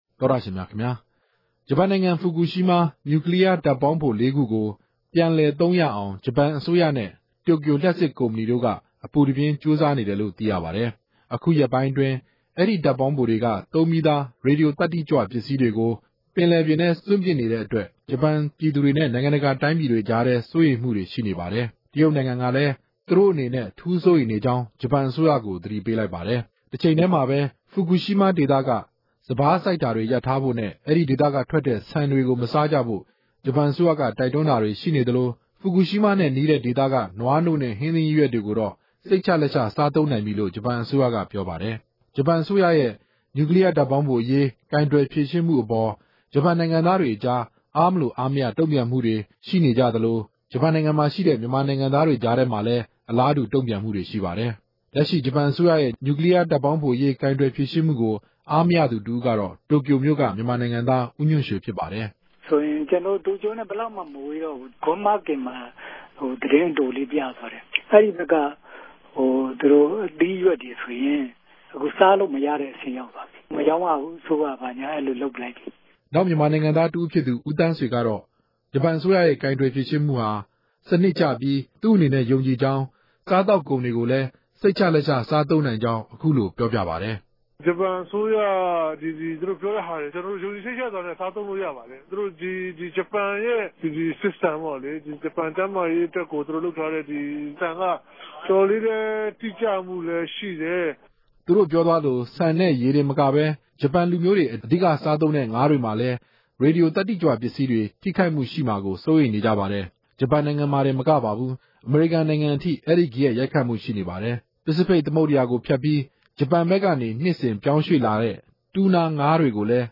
မေးမြန်းတင်ပြချက်။